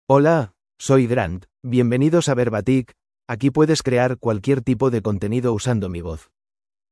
MaleSpanish (Spain)
Grant is a male AI voice for Spanish (Spain).
Voice sample
Listen to Grant's male Spanish voice.
Male
Grant delivers clear pronunciation with authentic Spain Spanish intonation, making your content sound professionally produced.